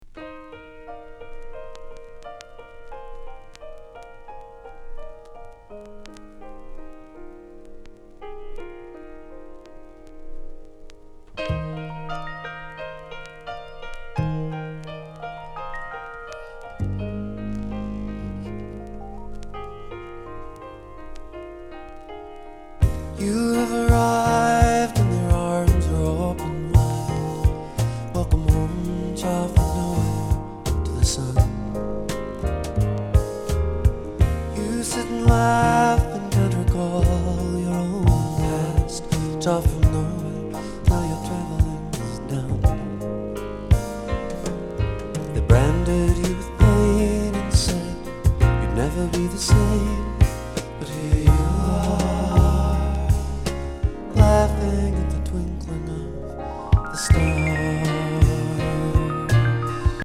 夏のAOR～ライトメロウクラシック！！！
ブリージン～リゾートなミドルナンバーたっぷり！